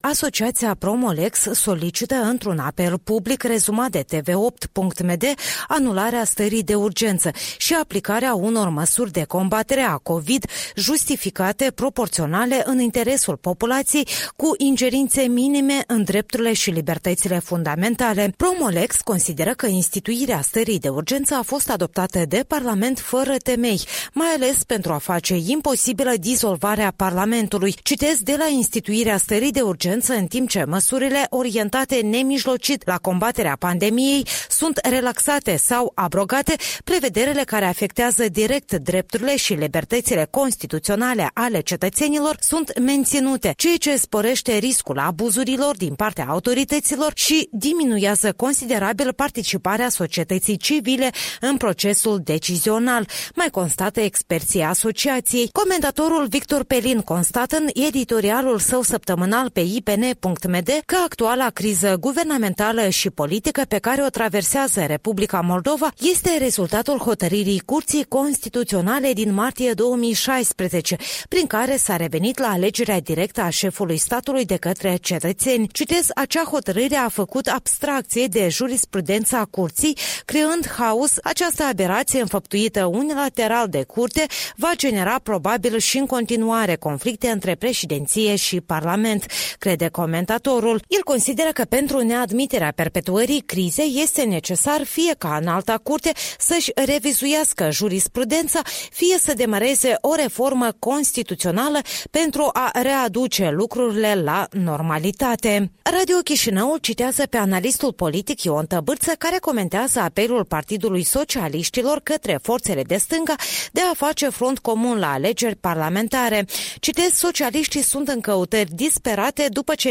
Revista matinală a presei la radio Europa Liberă.